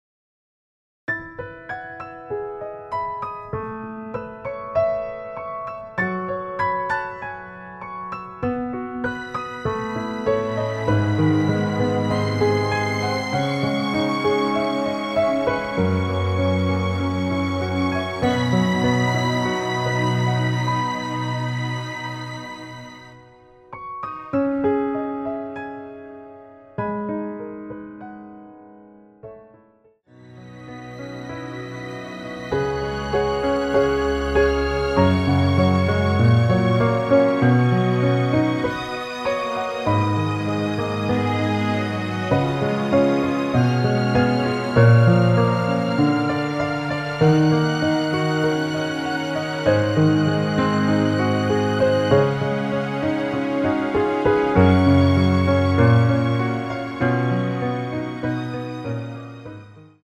원키에서(+4)올린 MR입니다.
C#m
앞부분30초, 뒷부분30초씩 편집해서 올려 드리고 있습니다.
중간에 음이 끈어지고 다시 나오는 이유는